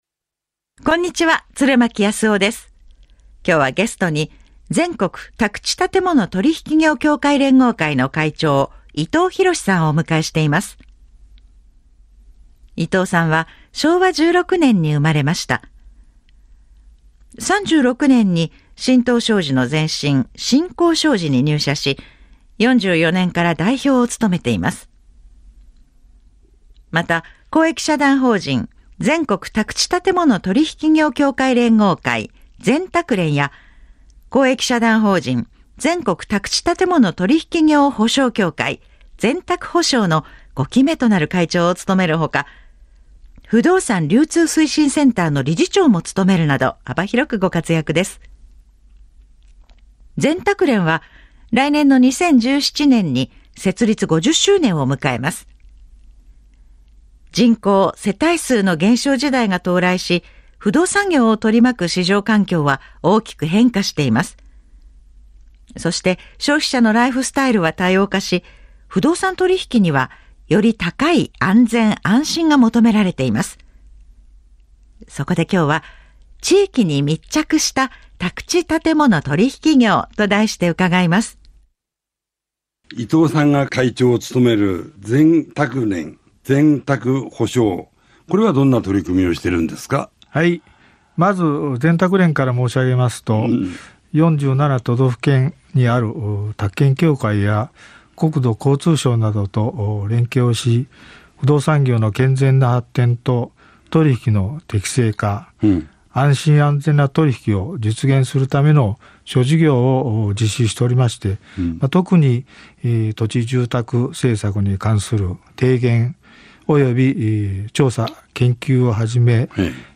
同番組は、経営者、政界、文化人など各界で活躍する第一人者の方々よりお話をうかがうもので、今年放送開始33年、8300回を突破する長寿番組。